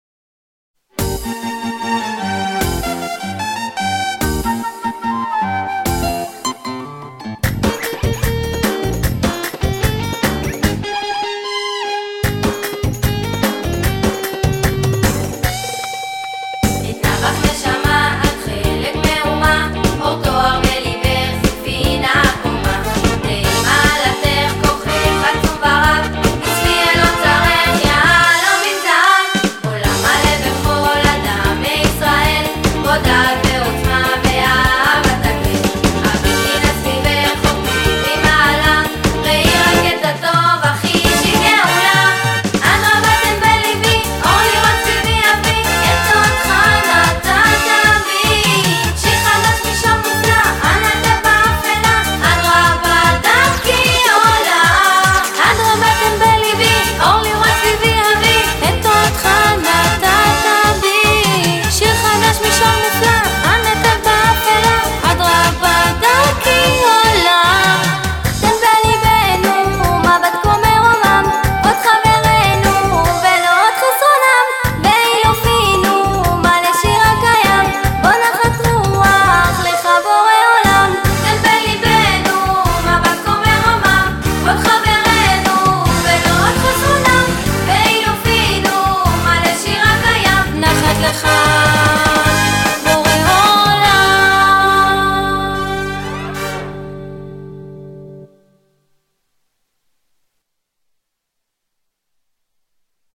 מיקס להמנון-שירת נשים